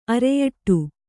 ♪ areyaṭṭu